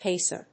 音節pác・er 発音記号・読み方
/ˈpesɝ(米国英語), ˈpeɪsɜ:(英国英語)/